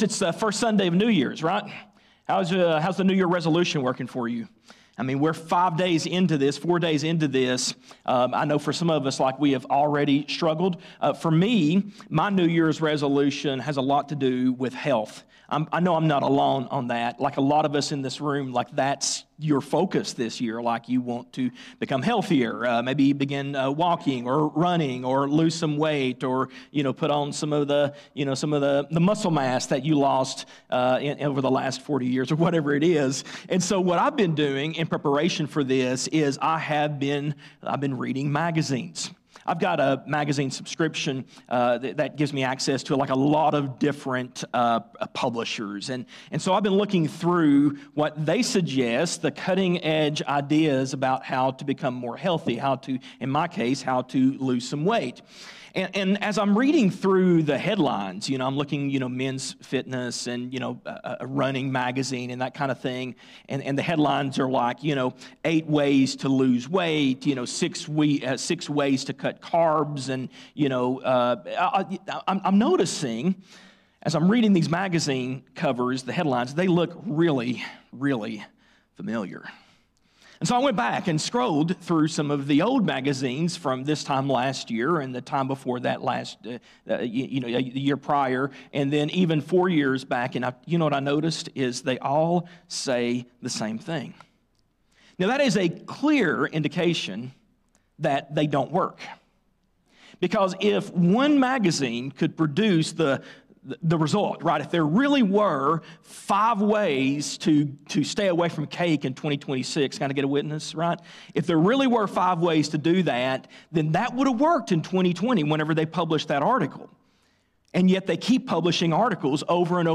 Sermons | Christiansburg Baptist Church | Christiansburg, VA